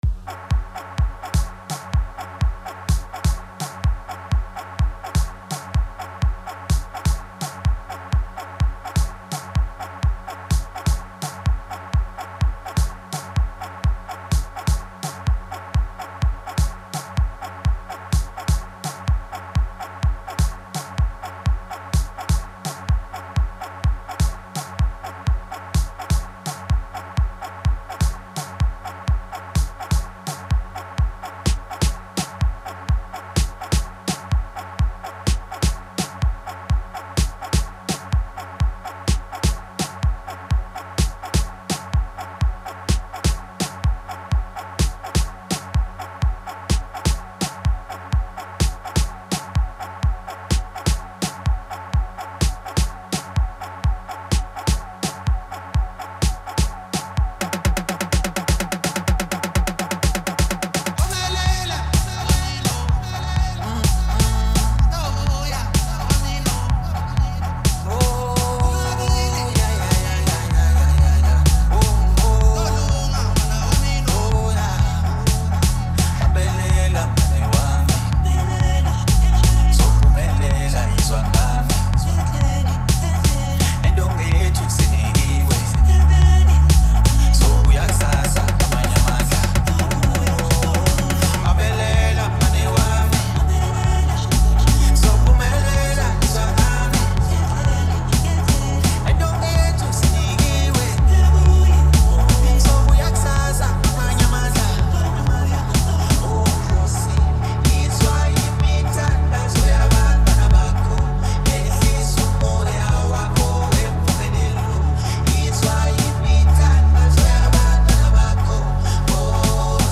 05:38 Genre : Gqom Size